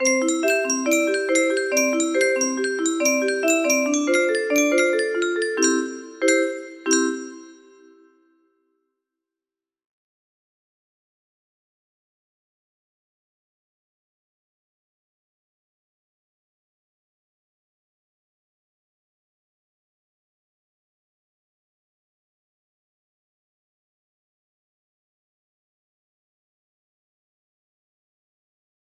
little tune music box melody